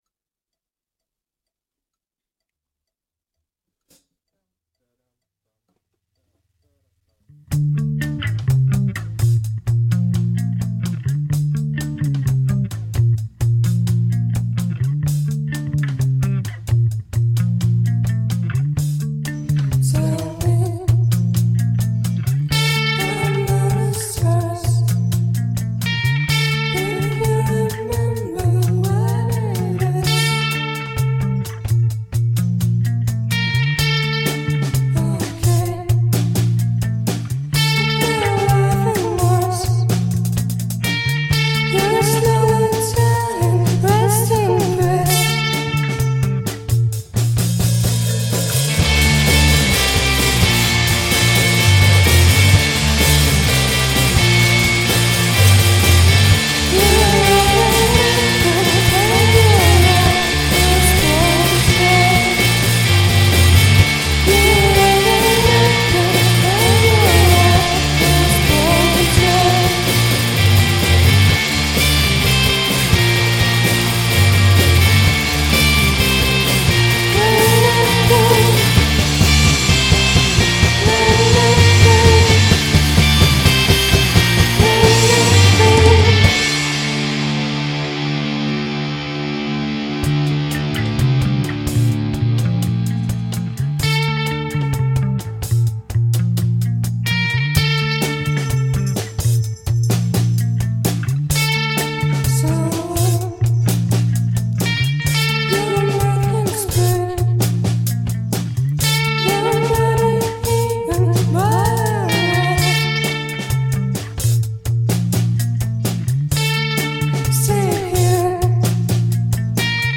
Russian rock band
Originally was making dream-pop music at home.
indie rock
minimalistic sounding of post-punk